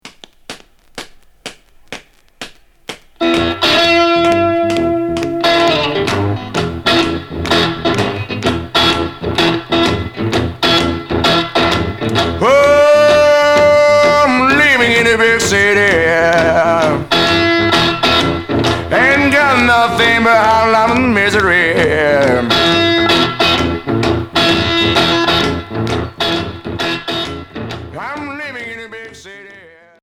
Bluesbilly